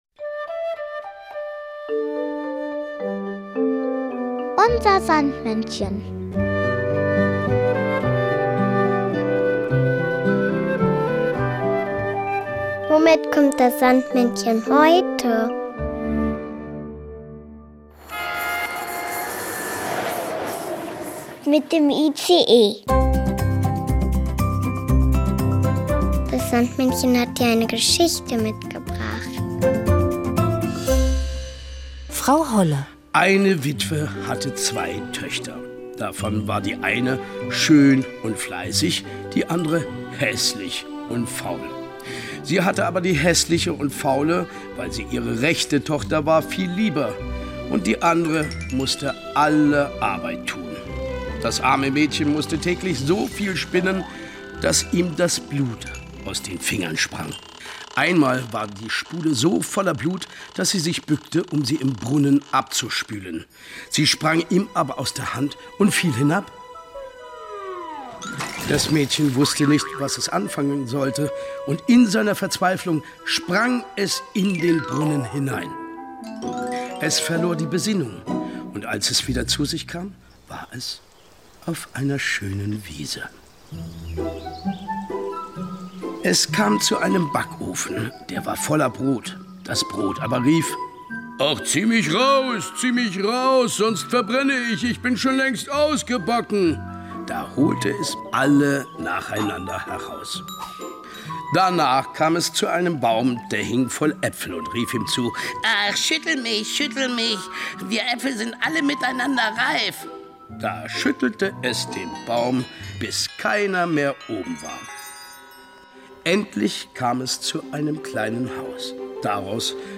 Der Schauspieler Axel Prahl erzählt das Märchen von Frau Holle.